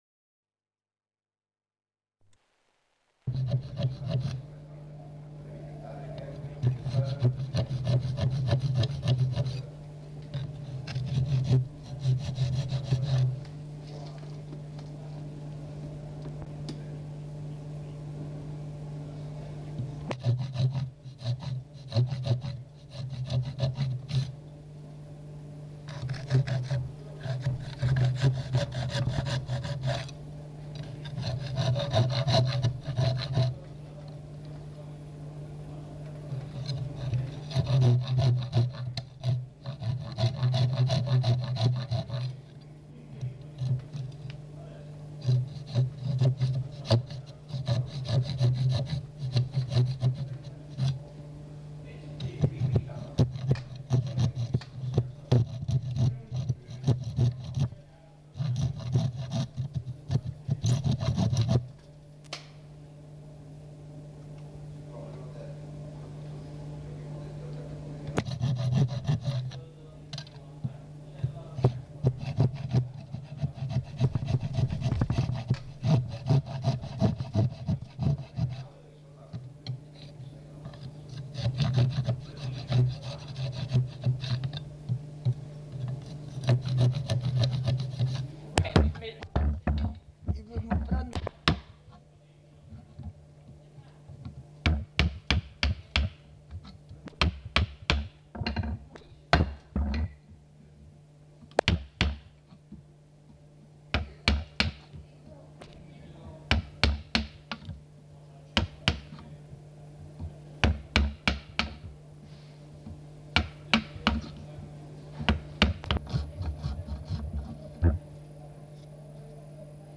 Bruits de travail du bois
Lieu : [sans lieu] ; Gers
Genre : paysage sonore